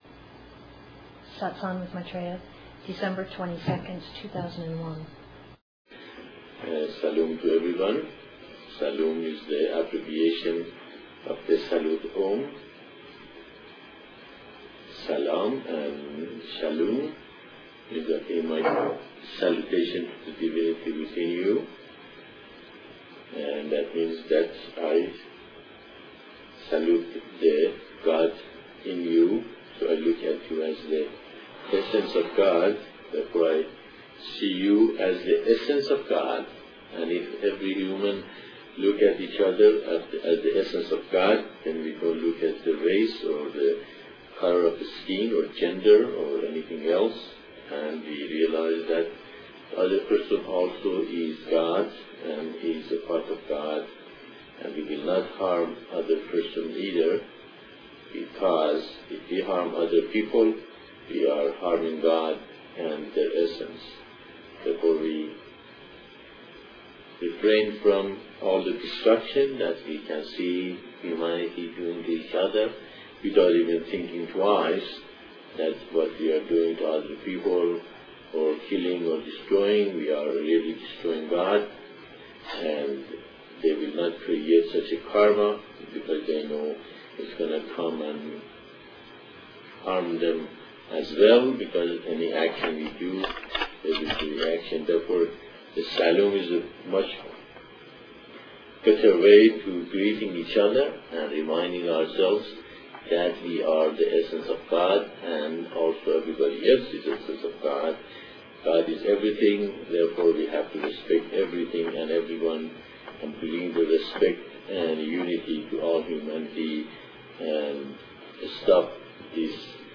Satsang (Discourse)